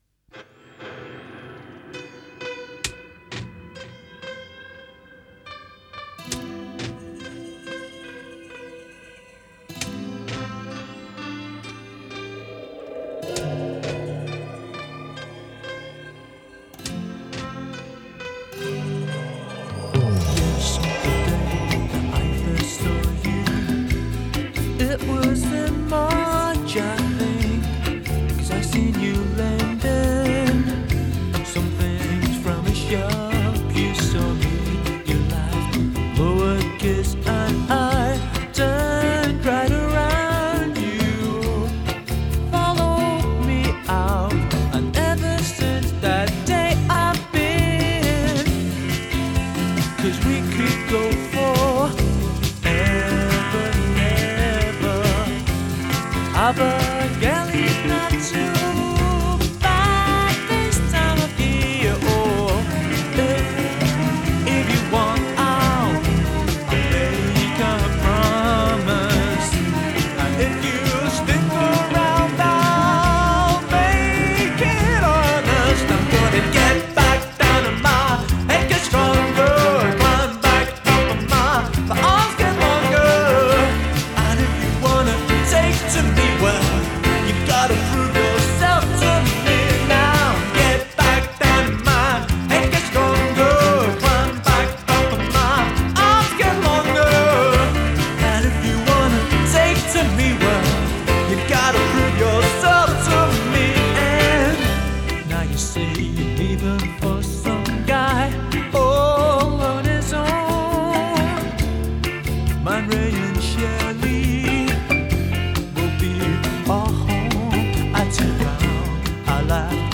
Genre: Indie, Lo-Fi, New Wave